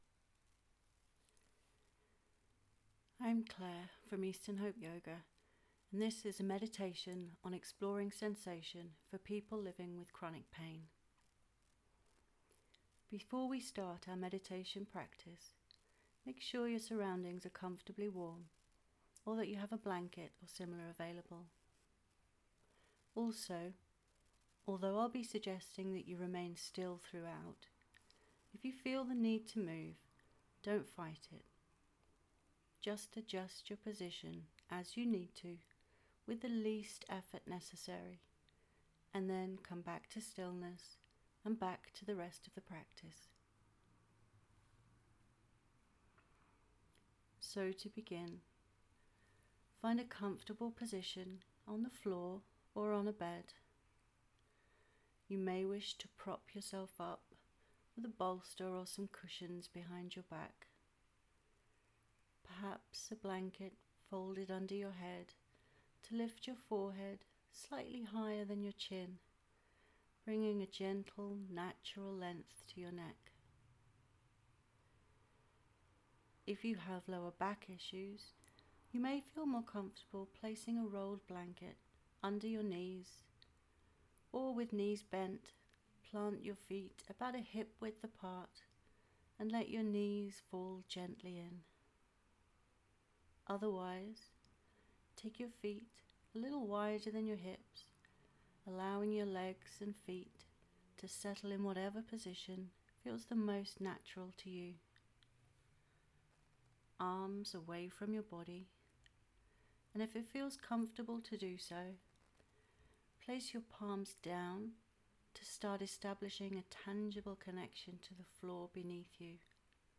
A Meditation on Exploring Sensation for Chronic Pain